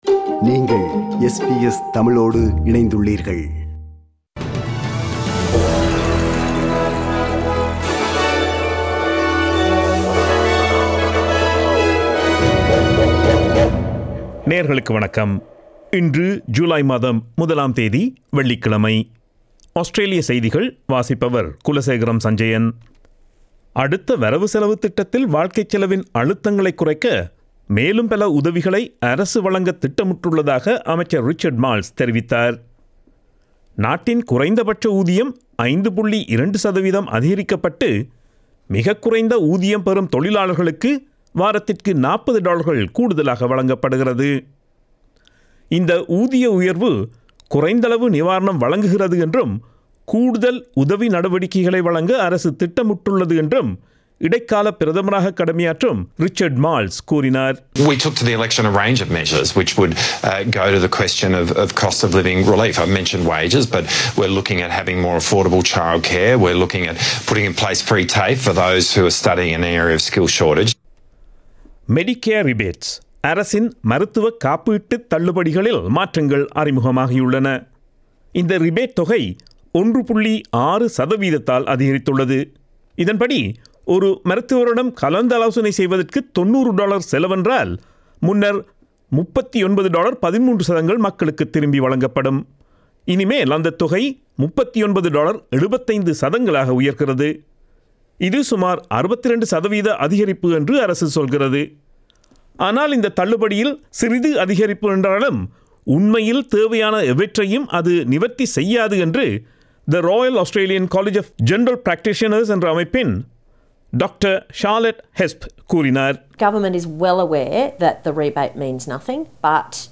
Australian news bulletin for Friday 01 July 2022.